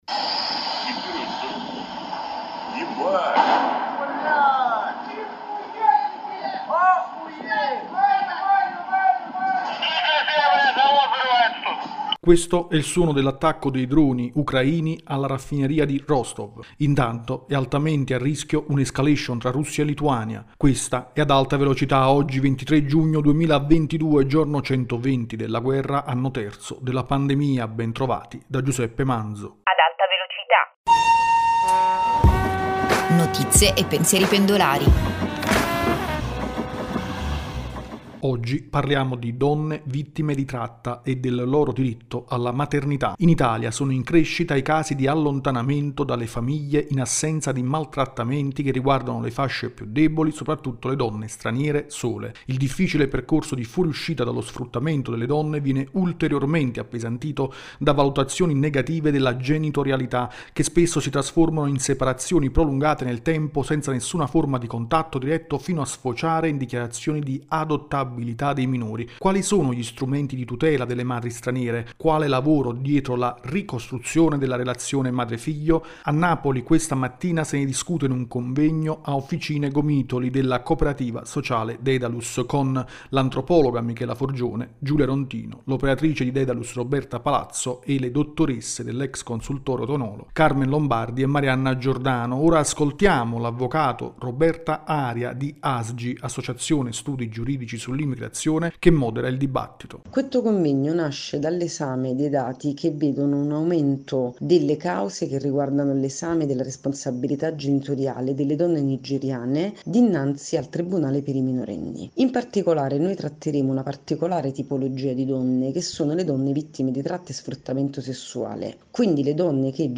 La voce di una giovanissima Regina Elisabetta nel giorno del suo insediamento nel 1947: se ne va una testimone che lega il secolo breve con la nuova era degli anni 2000.